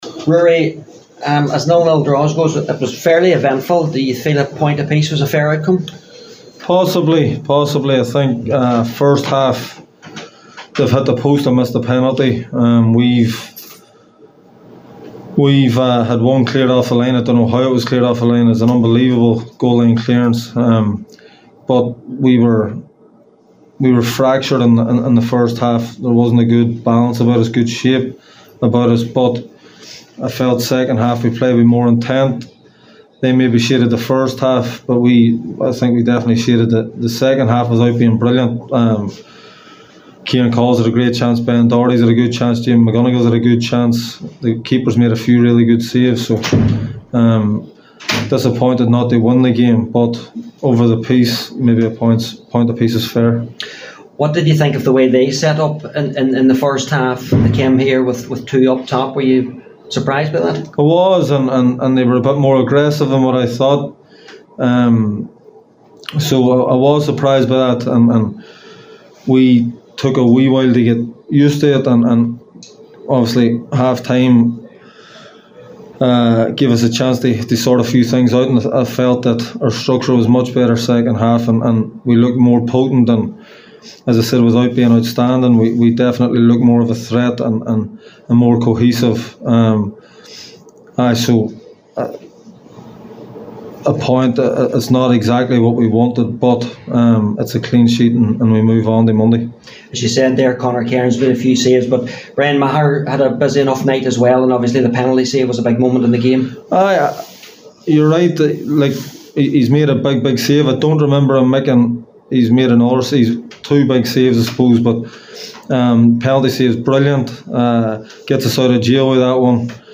spoke to the media after the game…